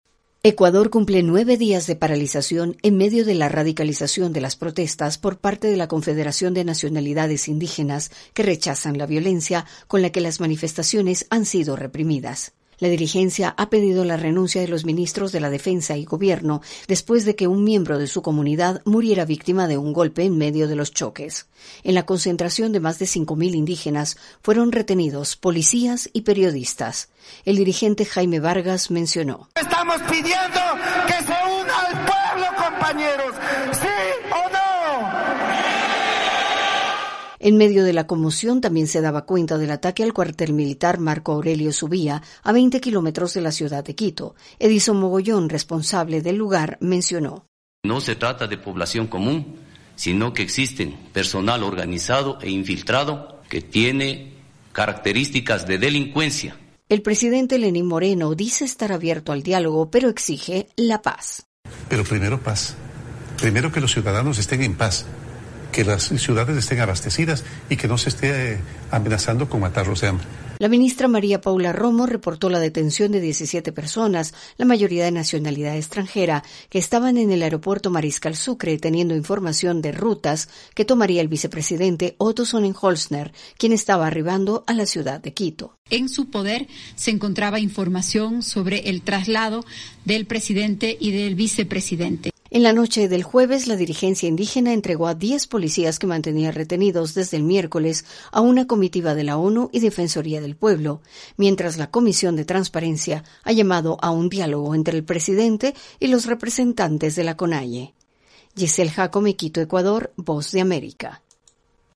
VOA: INFORME DE ECUADOR